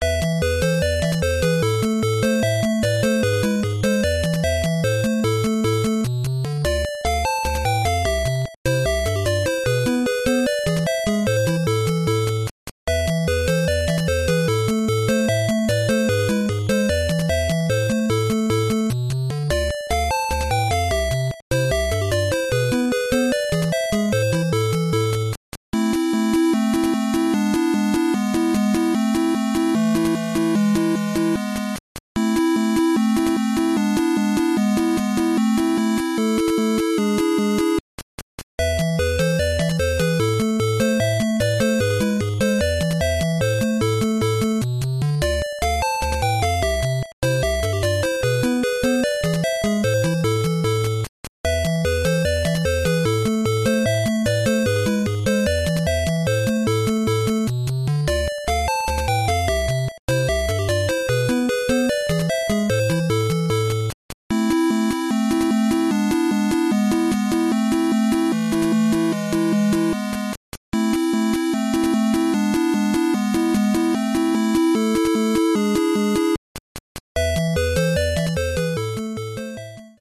19 SFX Sound Effects